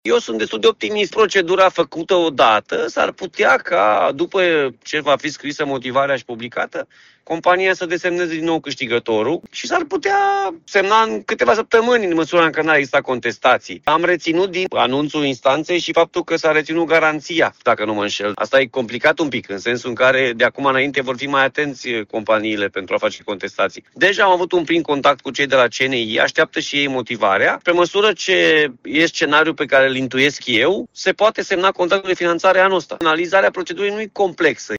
Președintele Consiliului Județean Timiș, Alfred Simonis, spune că, în funcție de data comunicării motivării, desemnarea noului câștigător, se poate face până la sfârșitul anului.